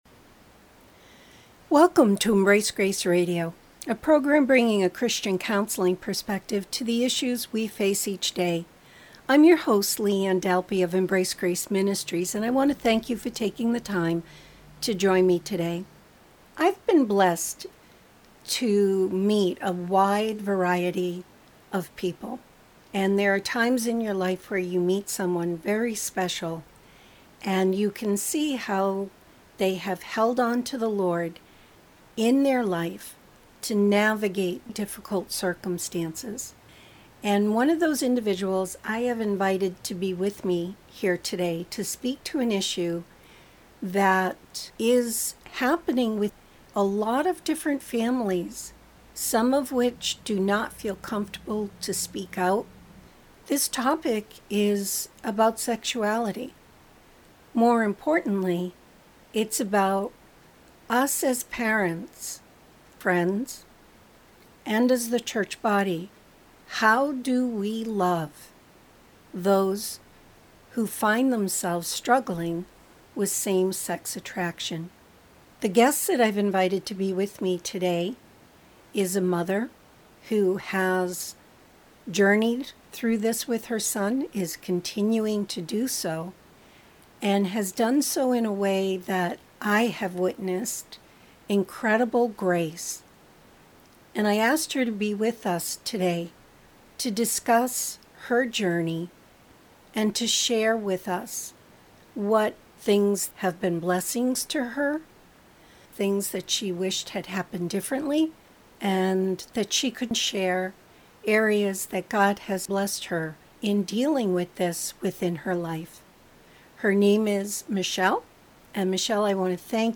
A radio show airing every Saturday at 2:30 EST. The show takes calls from real people going through life and personal problems looking for answers from a godly prespecrtive.